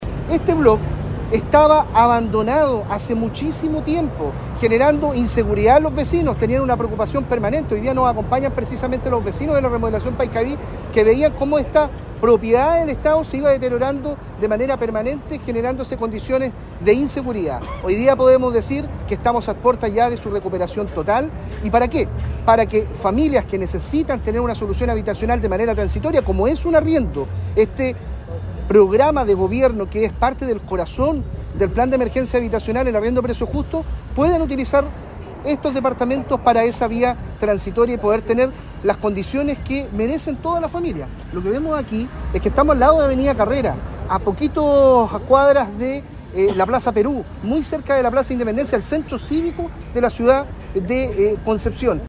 “Este block estaba abandonado hace muchísimo tiempo y generaba inseguridad en los vecinos”, argumentó el delegado presidencial, Eduardo Pacheco, sobre la rehabilitación de este lugar.
Arriendo-Justo-2-Delegado-Presidencial.mp3